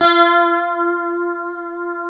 F#4 HSTRT MF.wav